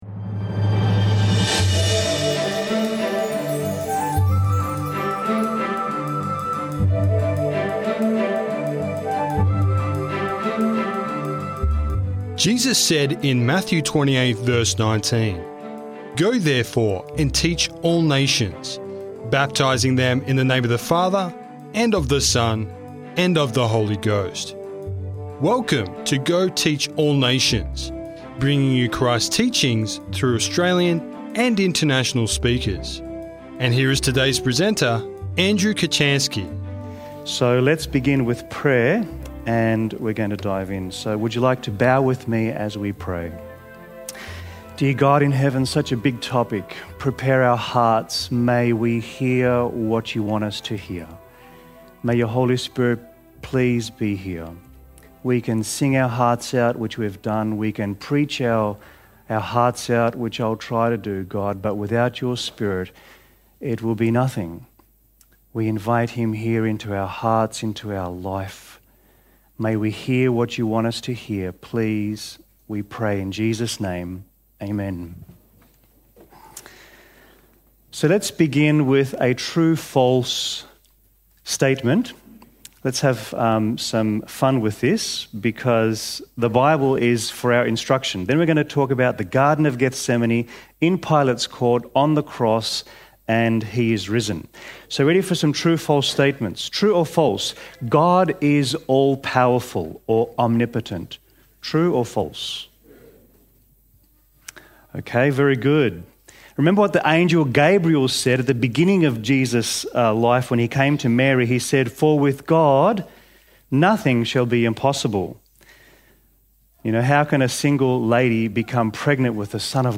Journey through this sermon from the Garden of Gethsemane to the victorious resurrection of Jesus Christ. Explore how God's omnipotence, omniscience, and omnipresence provide comfort and hope amid suffering. Find out how the cross transforms sorrow into joy and offers victory over life's toughest battles.